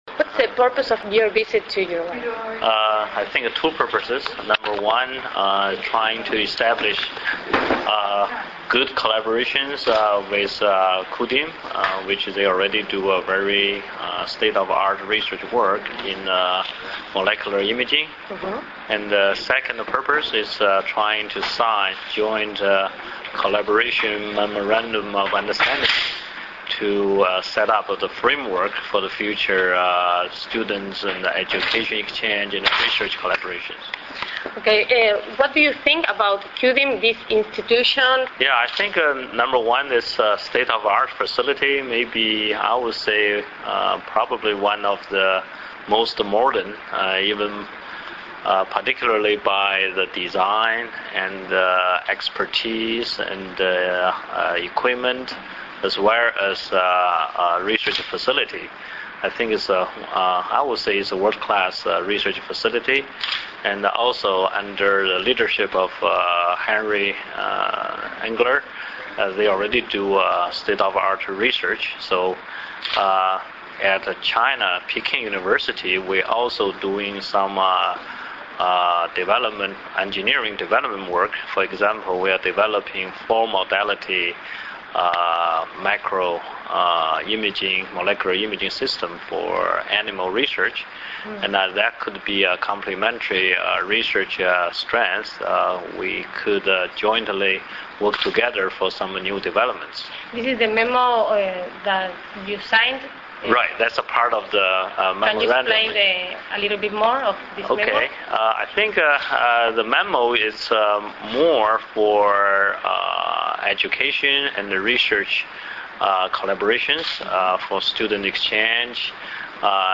Declaraciones a la prensa del Departamento de Ingeniería Biomédica de la Universidad de Pekín